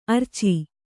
♪ arci